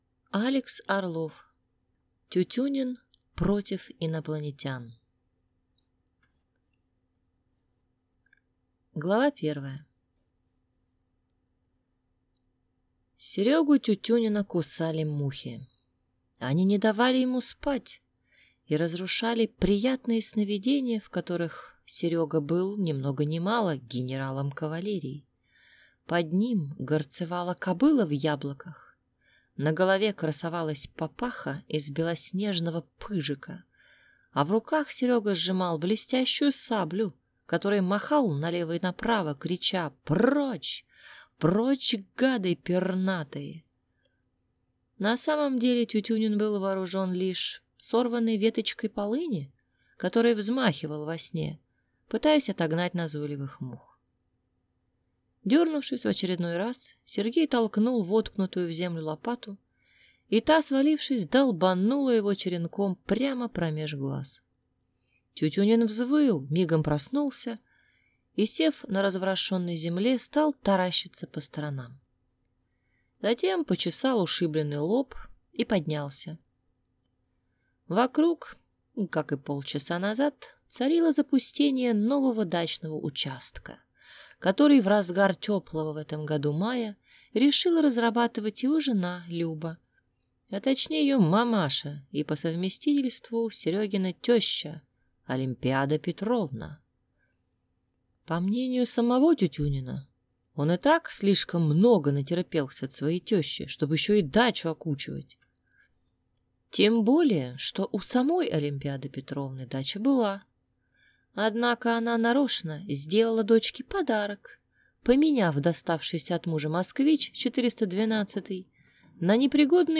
Аудиокнига Тютюнин против инопланетян | Библиотека аудиокниг
Прослушать и бесплатно скачать фрагмент аудиокниги